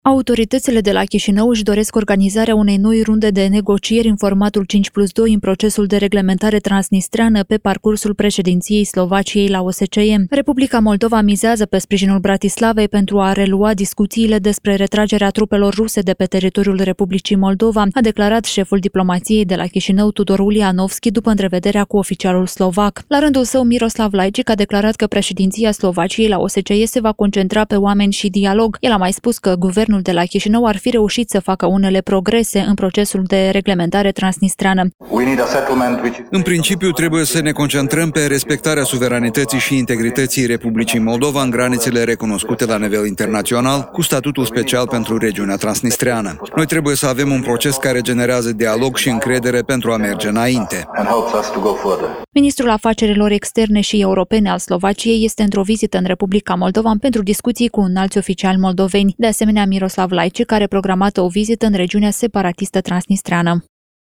Evenimente